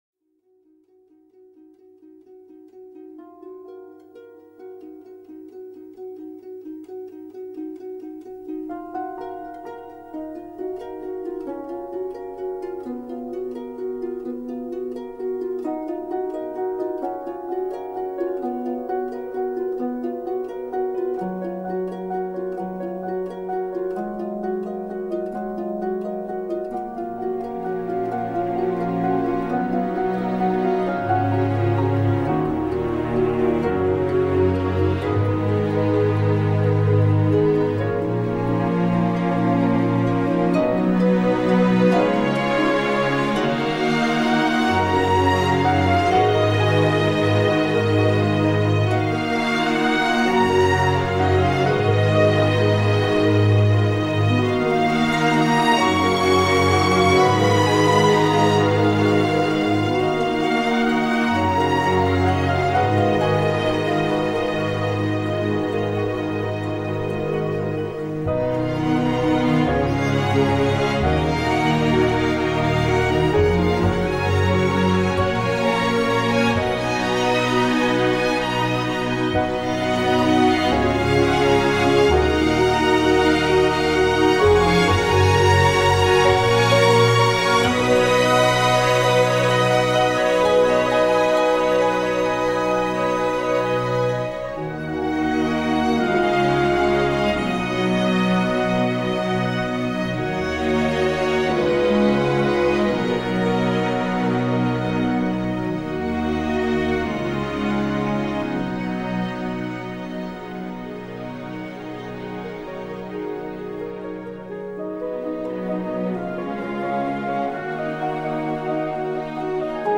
Ending Scene Music